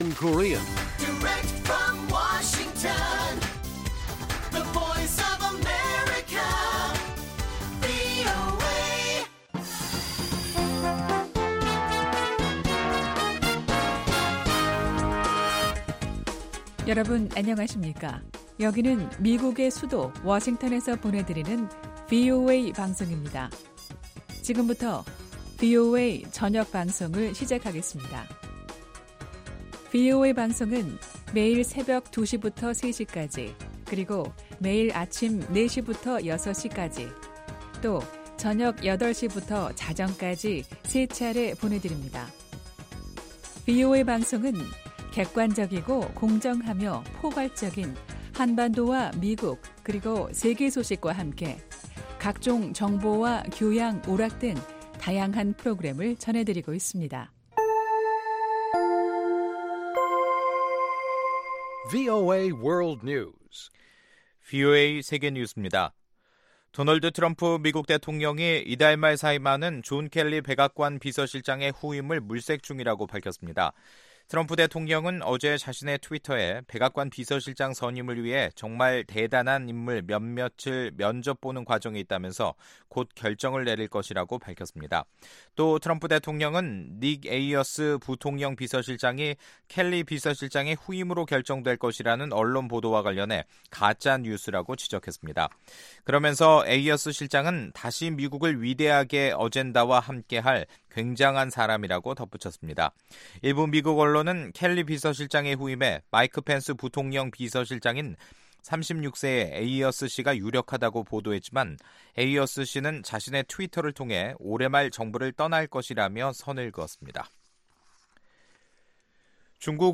VOA 한국어 간판 뉴스 프로그램 '뉴스 투데이', 2018년 12월 10일 1부 방송입니다. 북한은 핵무기 개발에 들어가는 자금을 국민복지로 사용한다면 인도적 문제를 자체 해결할 수 있을 것이라고 미 국무부 관계자가 말했습니다. 유엔 안보리가 4년 연속 개최한 북한의 인권 관련 회의가 올해는 정족수 부족으로 열리지 않게 됐습니다.